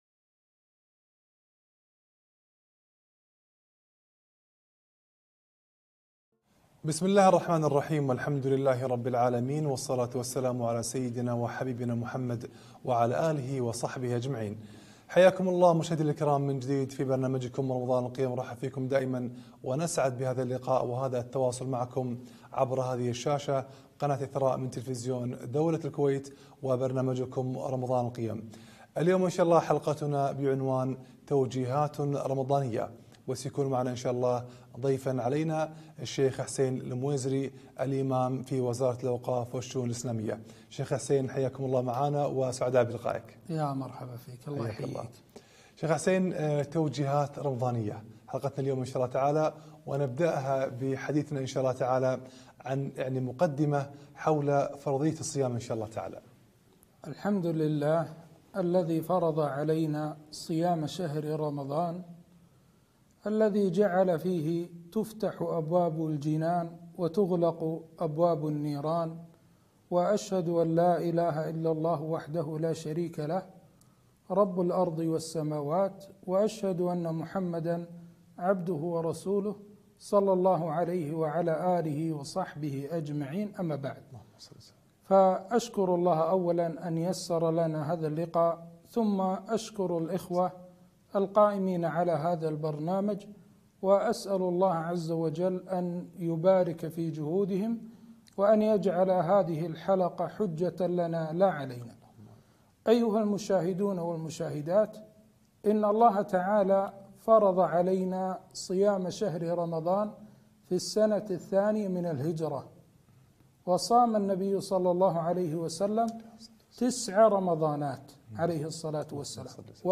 توجيهات رمضانية - لقاء تلفزيوني على قناد إثراء